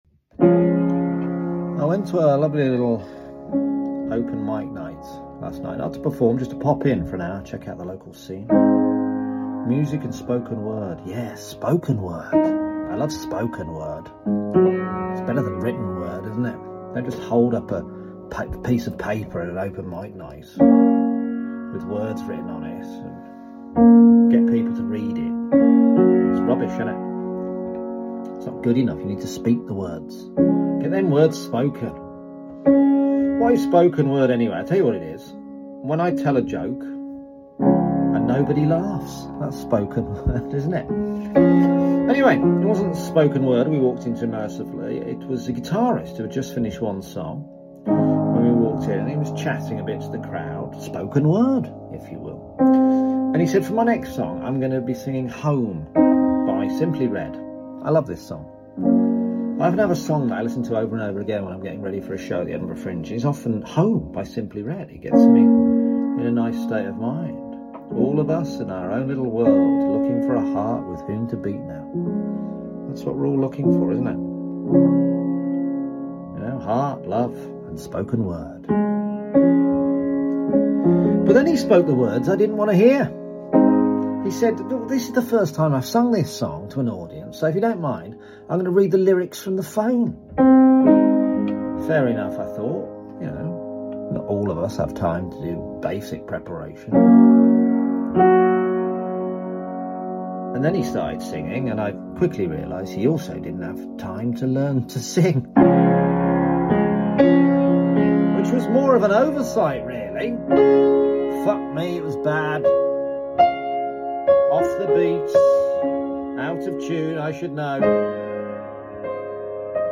Murdering songs.